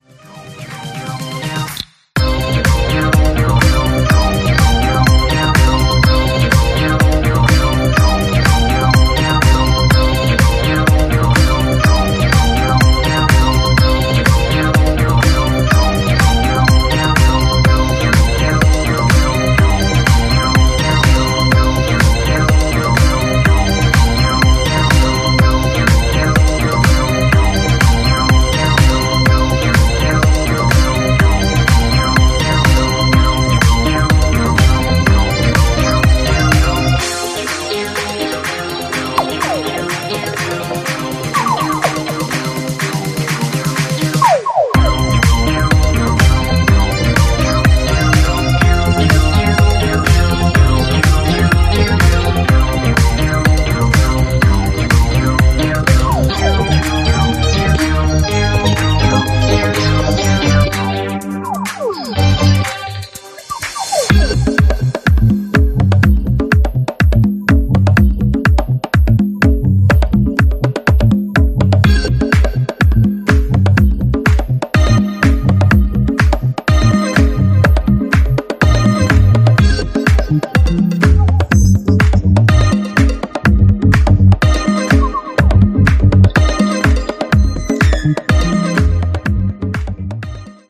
ジャンル(スタイル) DISCO / ITALO DISCO / BOOGIE